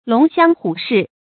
龍驤虎視 注音： ㄌㄨㄙˊ ㄒㄧㄤ ㄏㄨˇ ㄕㄧˋ 讀音讀法： 意思解釋： 象龍馬高昂著頭，象老虎注視著獵物。